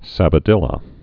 (săbə-dĭlə, -dēə)